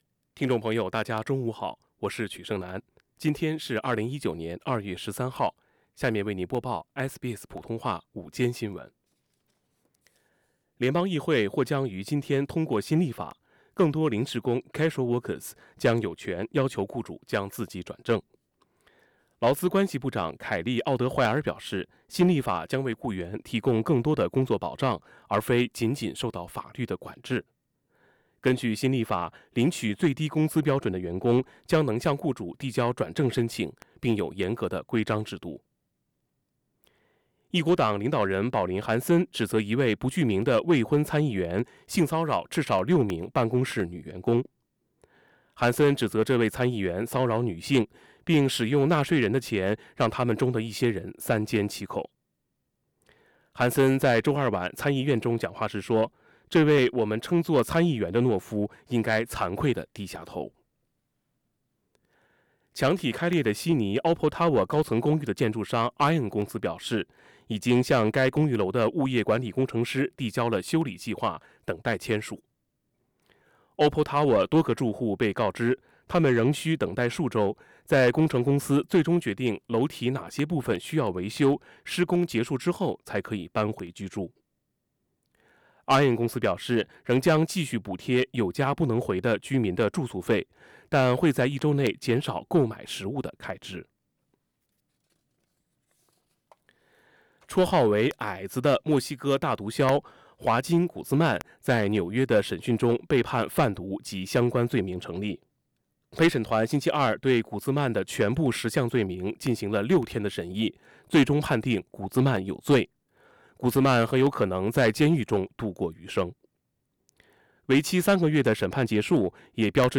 You are now with SBS Radio Chinese Program streaming with SBS Chinese Midday News.